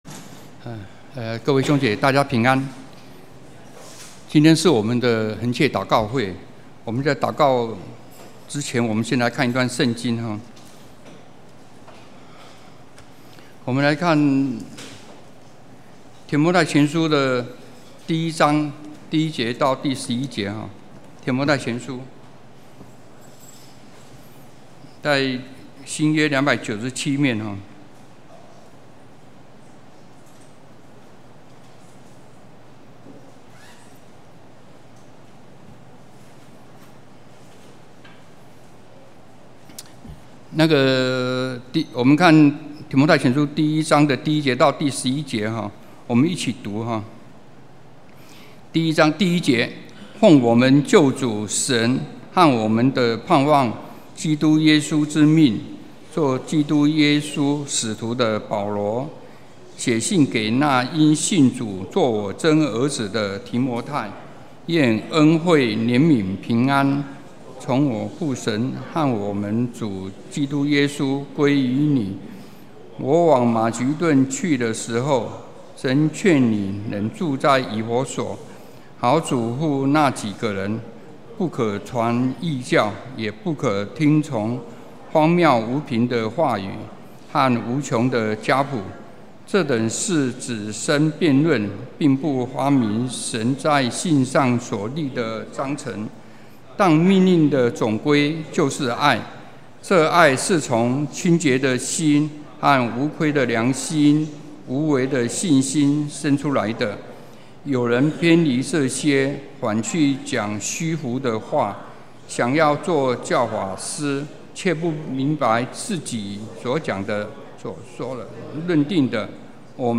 2019年3月份講道錄音已全部上線